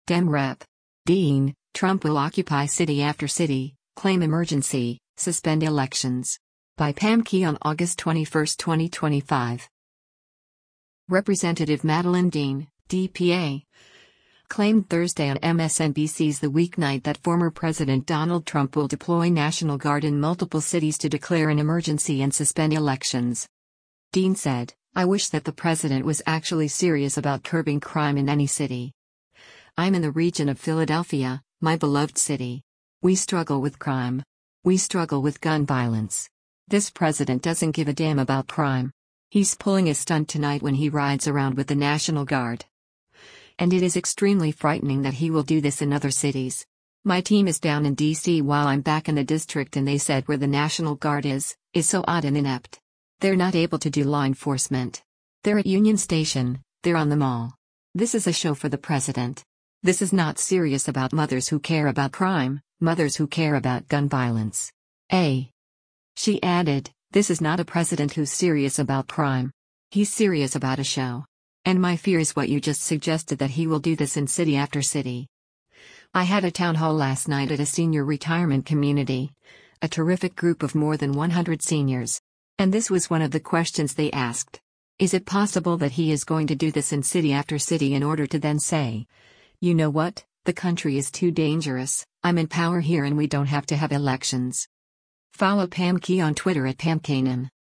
Representative Madeleine Dean (D-PA) claimed Thursday on MSNBC’s “The Weeknight” that former President Donald Trump will deploy National Guard in multiple cities to declare an emergency and suspend elections.